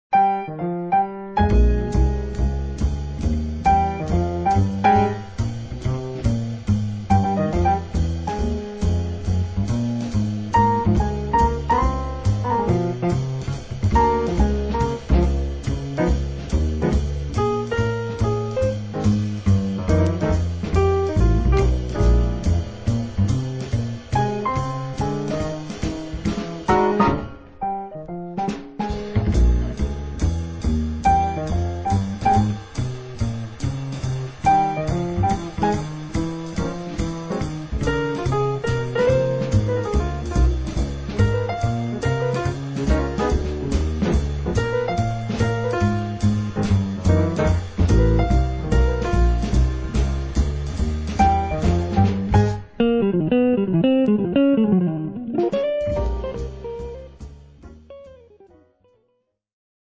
la registrazione predilige toni molto soft, empatici